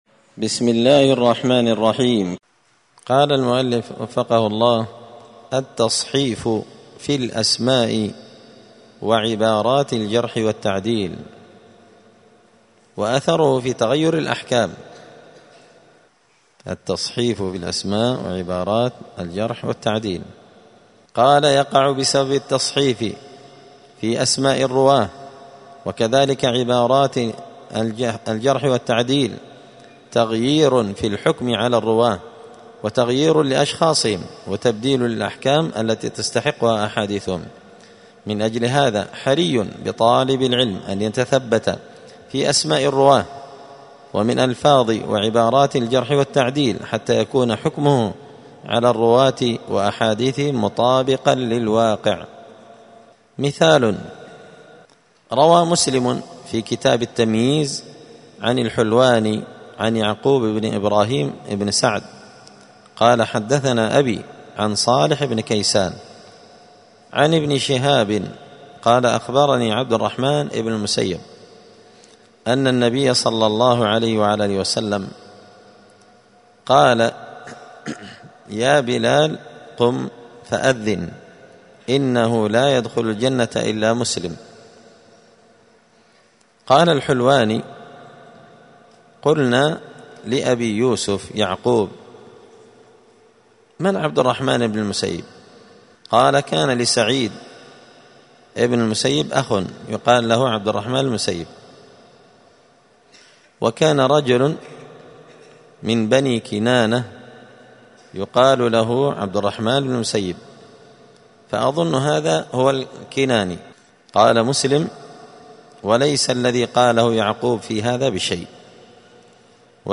*الدرس الرابع والثلاثون (34) باب التصحيف في الأسماء وعبارات الجرح والتعديل وأثره في تغير الأحكام*
دار الحديث السلفية بمسجد الفرقان بقشن المهرة اليمن